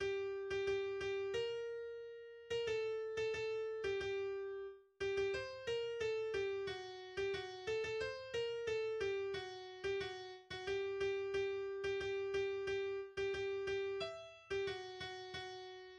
\header { tagline = "" } \language "deutsch" \layout { indent = #0 } akkorde = \chordmode { \germanChords % \partial 4 f4 c:7 f4. c8 f4 c:7 f2 c:7 f c:7 f c:7 f } melodie = \relative c'' { \time 4/4 \tempo 4 = 90 \key b \major \autoBeamOff % \partial 4 g8. g16 g8 g b4.. b16 a8. a16 a8. g16 g4 r8 g16 g c8 b a g fis8. g16 fis8 a16 a c8 b a g fis8. g16 fis8 r16 fis16 g8 g g8. g16 g8 g g r16 g16 g8 g e'8. g,16 fis 8 fis fis4 \bar "|."